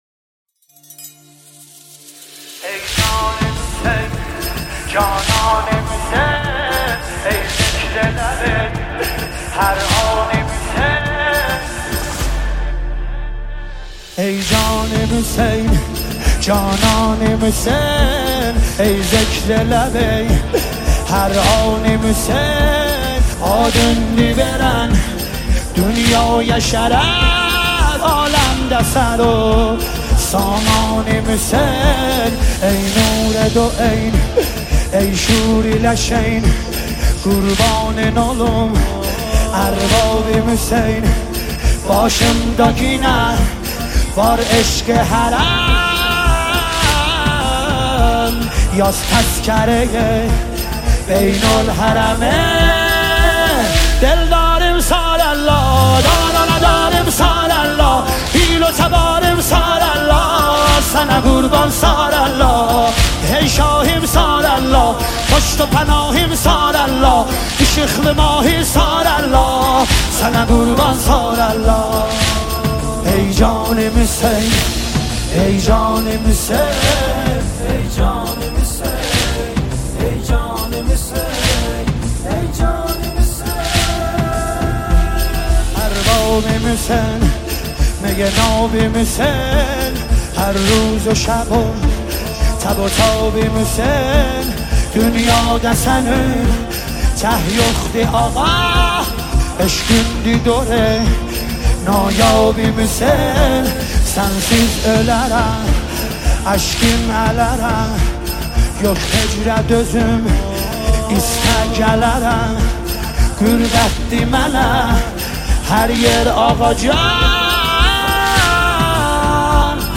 نماهنگ ترکی دلنشین